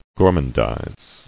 [gor·mand·ize]